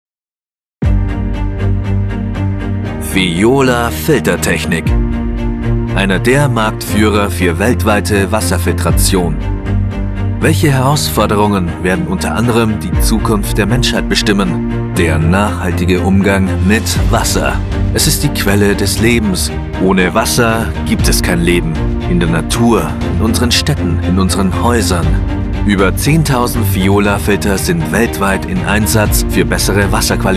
Diep, Stoer, Commercieel, Natuurlijk, Warm
Corporate